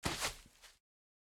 Minecraft Version Minecraft Version snapshot Latest Release | Latest Snapshot snapshot / assets / minecraft / sounds / mob / turtle / walk2.ogg Compare With Compare With Latest Release | Latest Snapshot